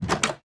Minecraft Version Minecraft Version 1.21.5 Latest Release | Latest Snapshot 1.21.5 / assets / minecraft / sounds / block / wooden_door / open2.ogg Compare With Compare With Latest Release | Latest Snapshot
open2.ogg